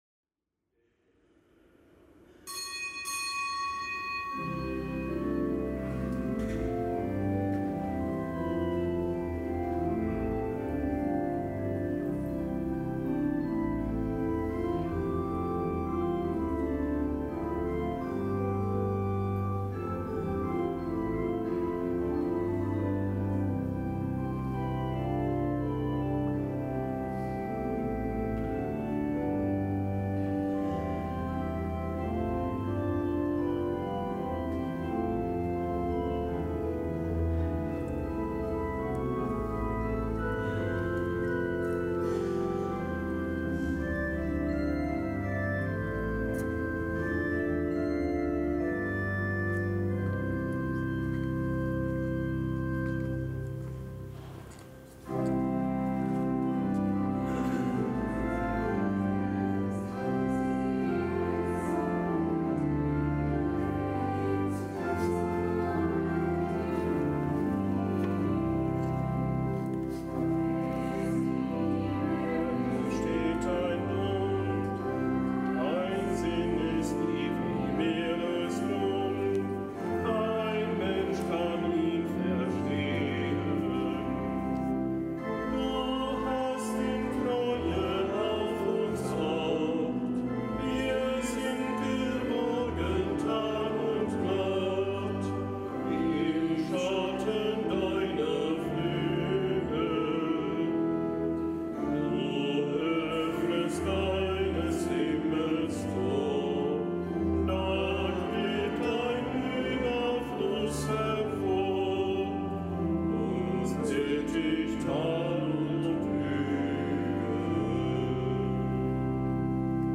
Kapitelsmesse aus dem Kölner Dom am Mittwoch der siebzehnten Woche im Jahreskreis.